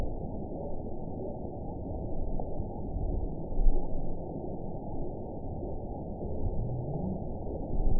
event 921766 date 12/18/24 time 23:42:10 GMT (11 months, 2 weeks ago) score 9.54 location TSS-AB03 detected by nrw target species NRW annotations +NRW Spectrogram: Frequency (kHz) vs. Time (s) audio not available .wav